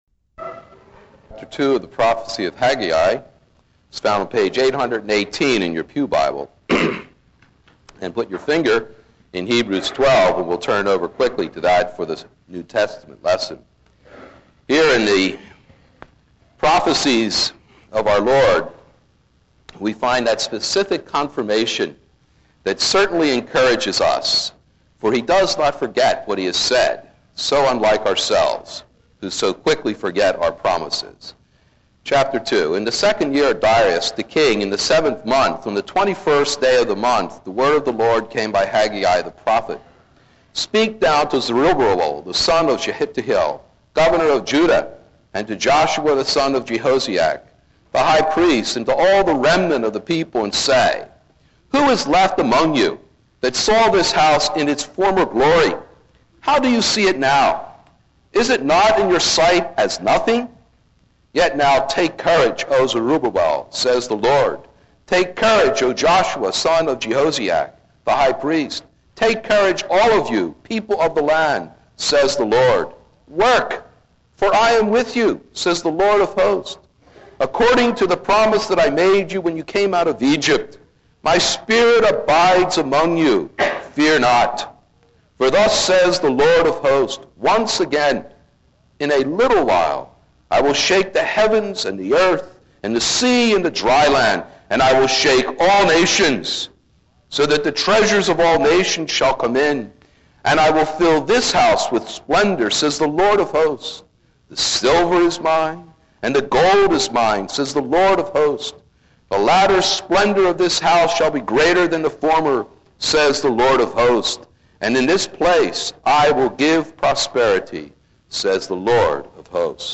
This is a sermon on Haggai 2:1-9.